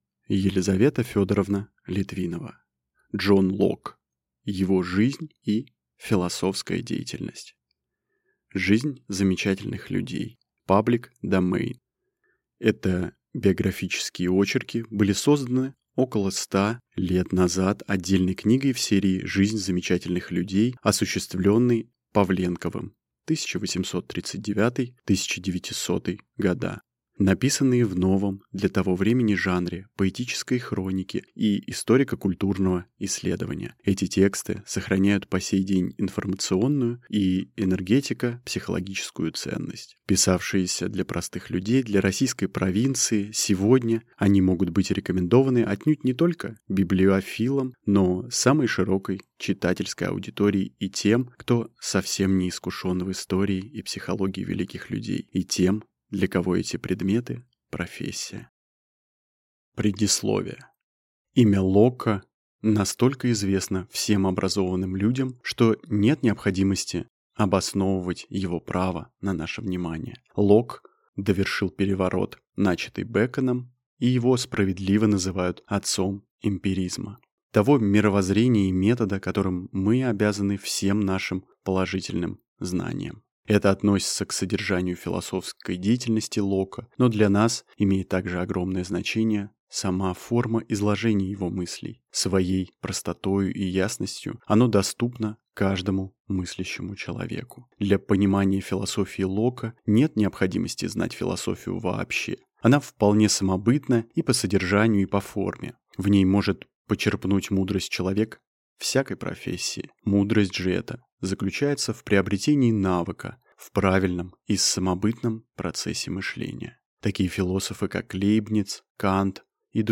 Аудиокнига Джон Локк. Его жизнь и философская деятельность | Библиотека аудиокниг